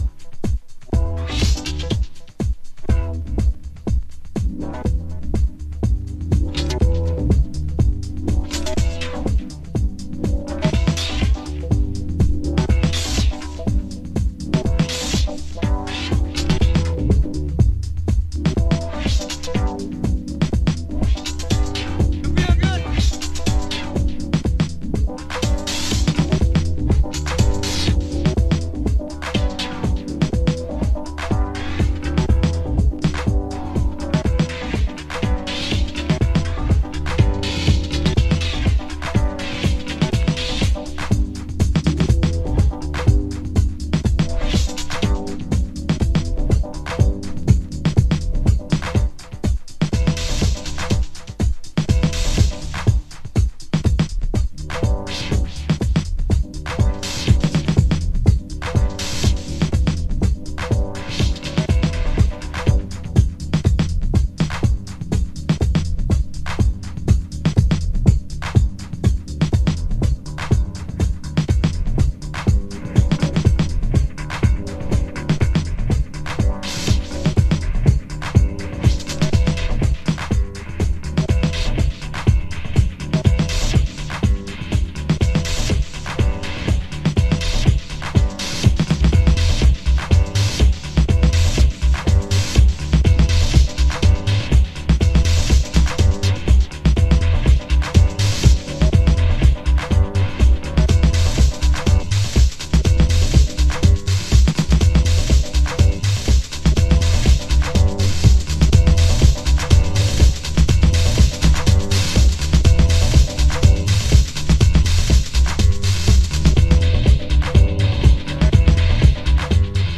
ラテンフュージョンをサンプリング・ループ、サウダージ・フィルターハウス。